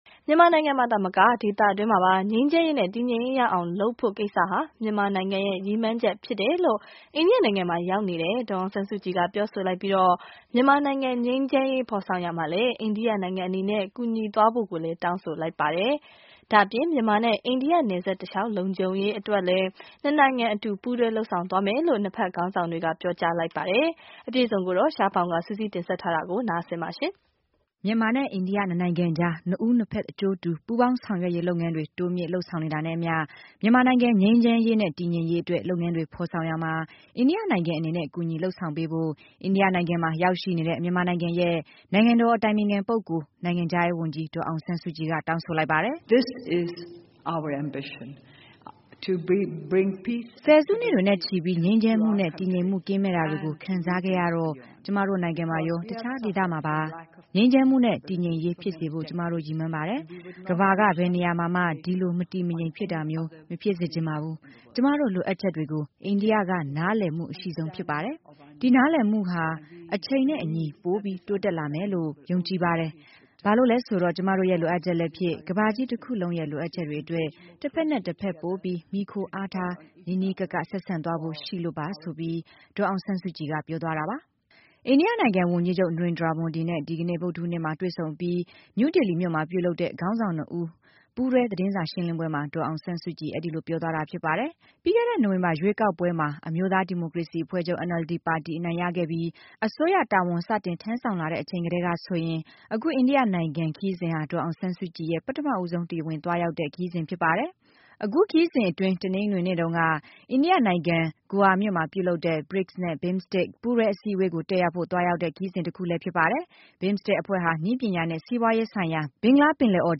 ဒေါ်စုနှင့် အိန္ဒိယဝန်ကြီးချုပ် တို့ရဲ့သတင်းစာရှင်းလင်းပွဲ
အိန္ဒိယနိုင်ငံ ဝန်ကြီးချုပ် Narendra Modi နဲ့ ဒီကနေ့ ဗုဒ္ဓဟူးနေ့မှာ တွေ့ဆုံပြီး နယူးဒေလီမြို့မှာပြုလုပ်တဲ့ ခေါင်းဆောင်နှစ်ဦး ပူးတွဲသတင်း စာရှင်းလင်းပွဲမှာ ဒေါ်အောင်ဆန်းစုကြည်အဲဒီလိုပြောသွား ပါတယ်။